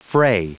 Prononciation du mot fray en anglais (fichier audio)
Prononciation du mot : fray